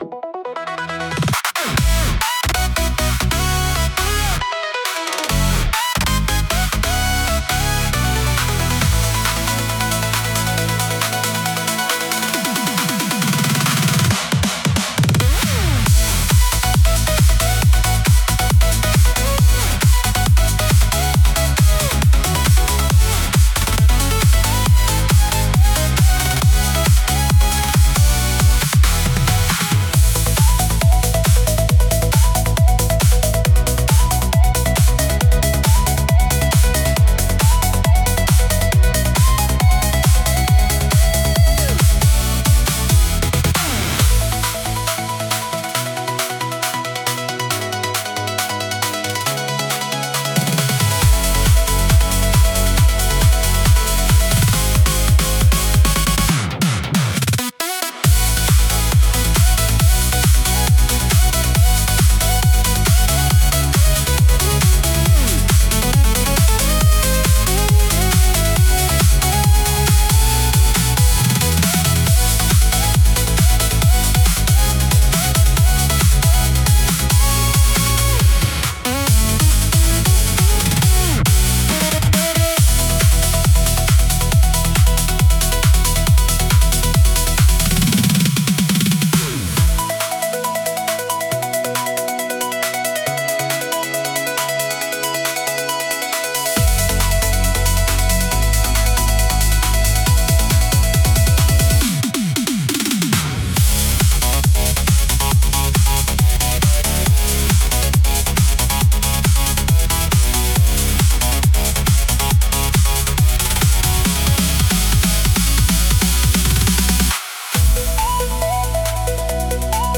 イメージ：インスト,EDM
インストゥルメンタル（instrumental）